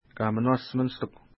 Kaminuasht minishtik u Next name Previous name Image Not Available ID: 238 Longitude: -60.8933 Latitude: 55.9815 Pronunciation: ka:minwa:s ministuk Translation: Beautiful Island Official Name: Kasungatak Island Feature: island